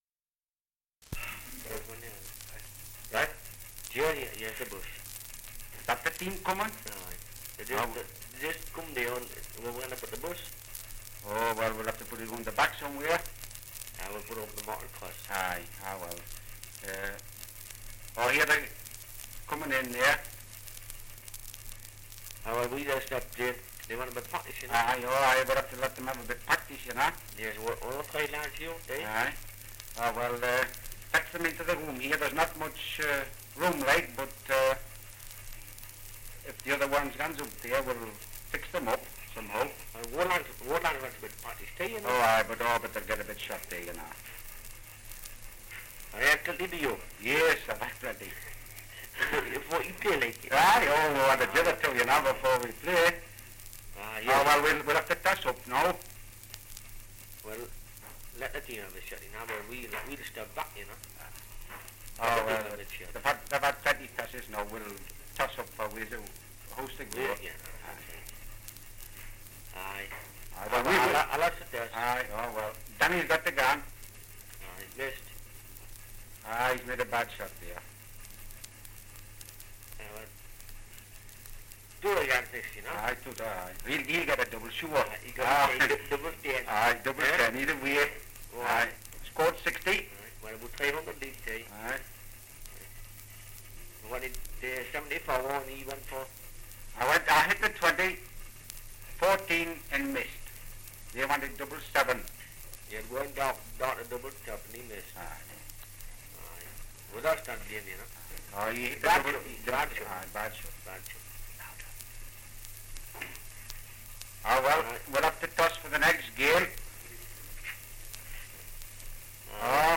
Dialect recording in Belford, Northumberland
78 r.p.m., cellulose nitrate on aluminium